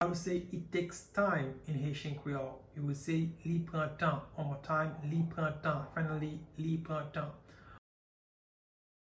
Pronunciation:
It-takes-time-in-Haitian-Creole-Li-pran-tan-pronunciation-by-a-Haitian-teacher.mp3